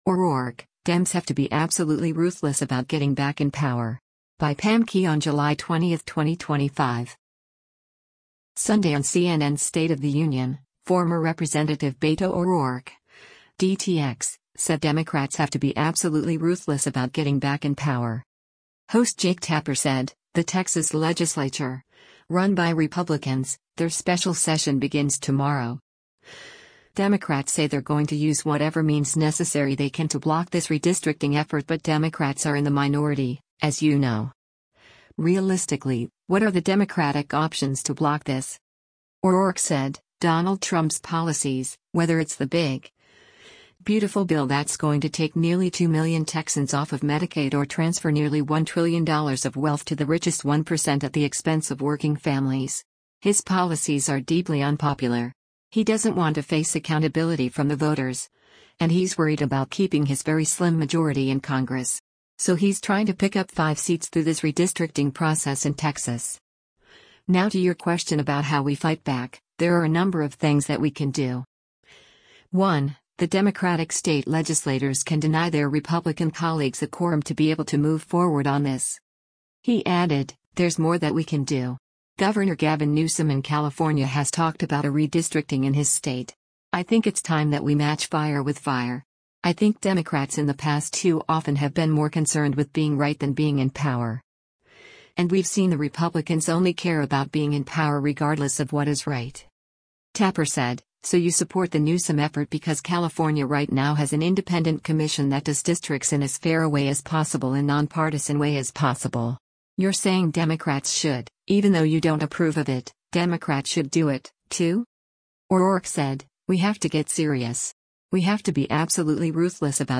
Sunday on CNN’s “State of the Union,” former Rep. Beto O’Rourke (D-TX) said Democrats have to be “absolutely ruthless about getting back in power.”